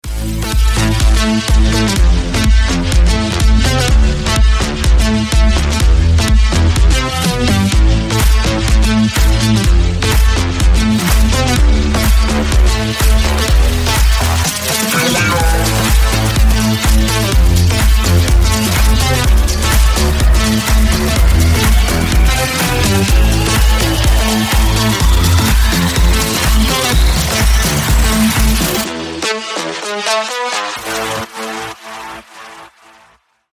Клубные Рингтоны » # Рингтоны Без Слов
Танцевальные Рингтоны